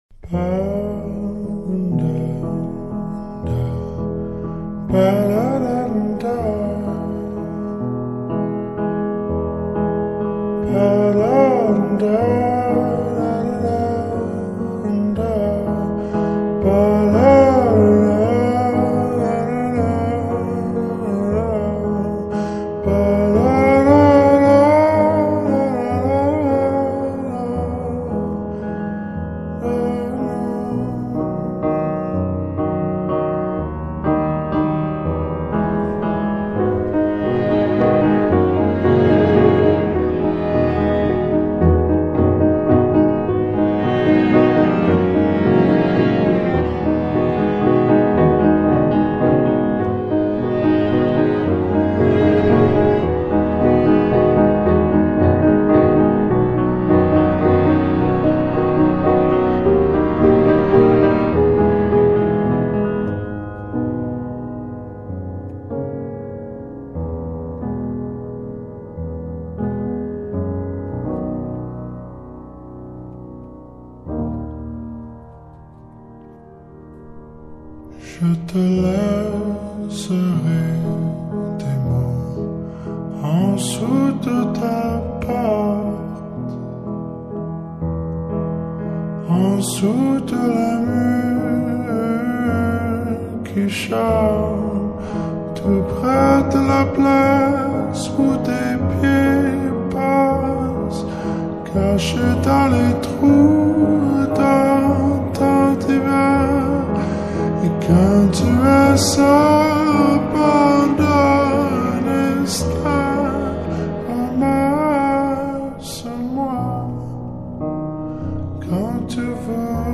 s l o w e d